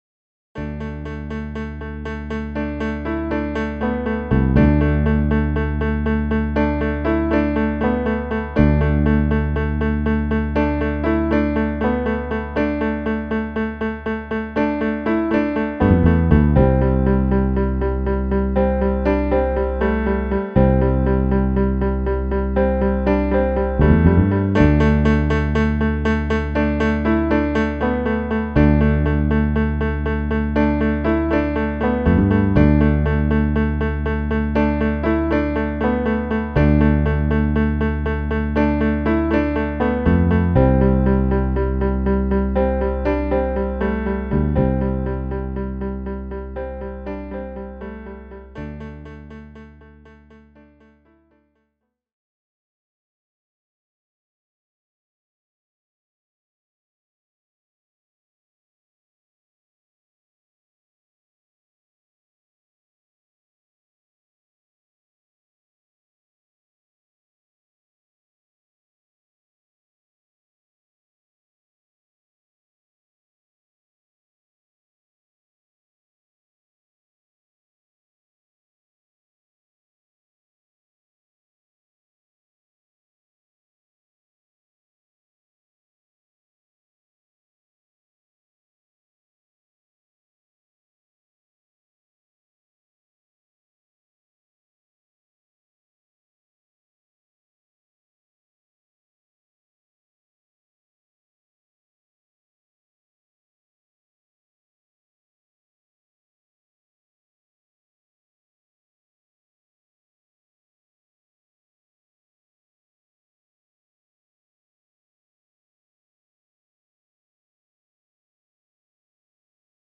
And there’s a bassline, and really quick drums.
The newsy tune